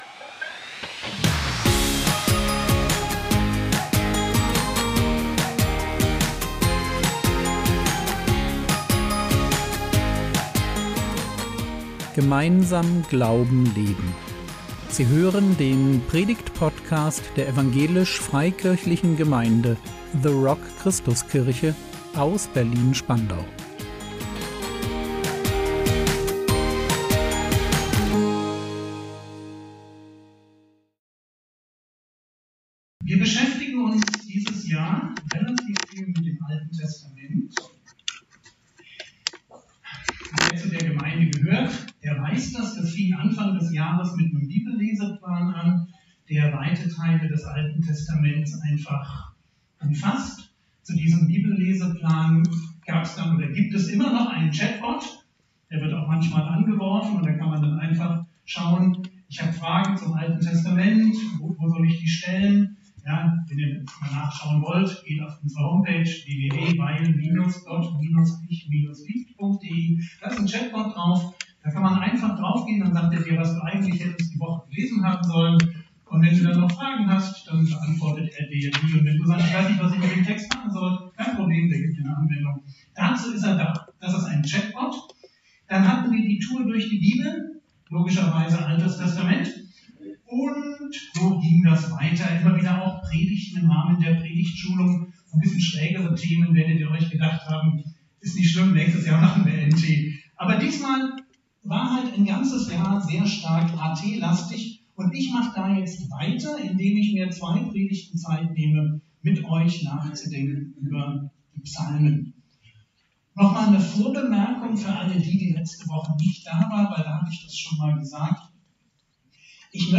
Psalmen - Teil 1 | 16.11.2025 ~ Predigt Podcast der EFG The Rock Christuskirche Berlin Podcast